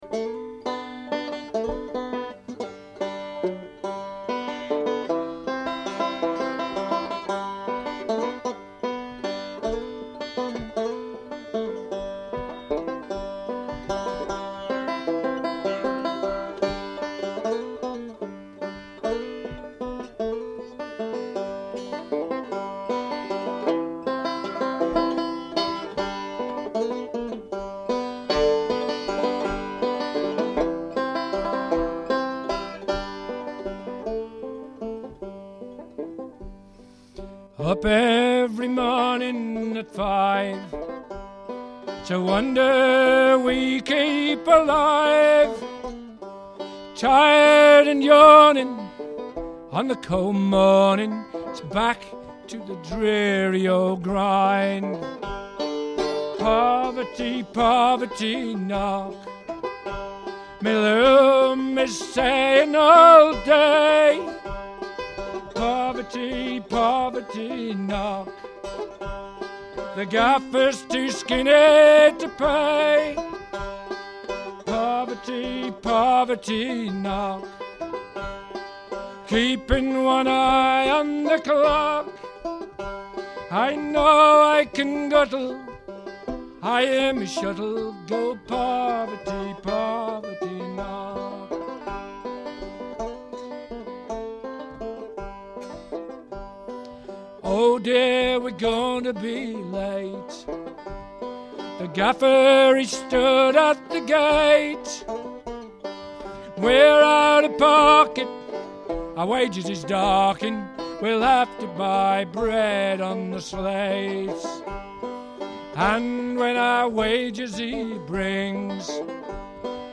Traditional Anglo American and a few modern pieces, usually in a traditional style, and sea songs and shanties.